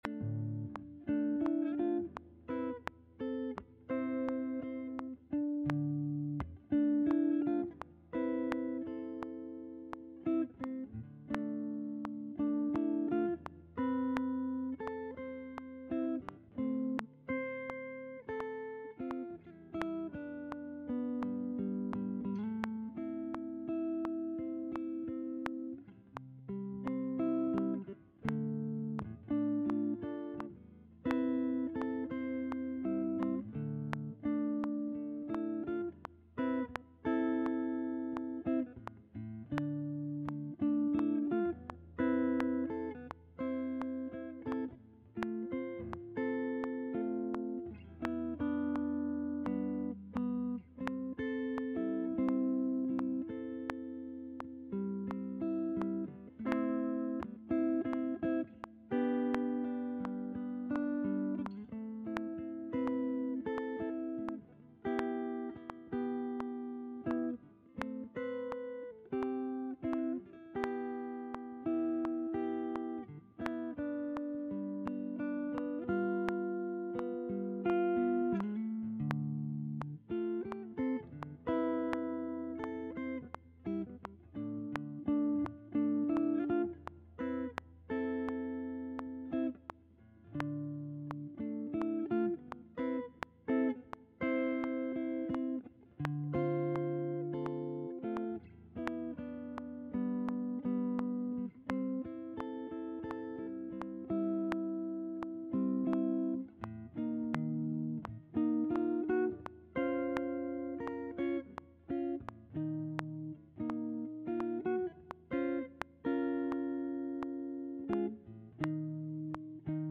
in Dm at 85 BPM.